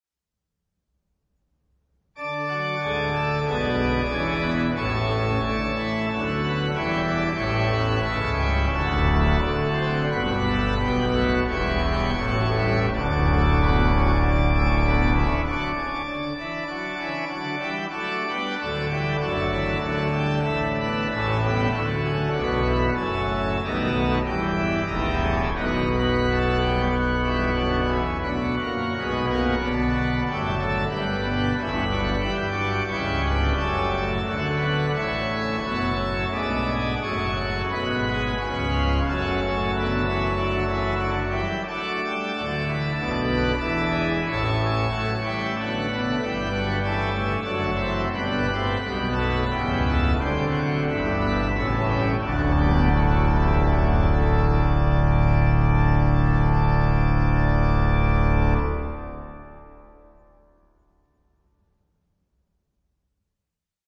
Christmas chorale prelude
standard organ repertoire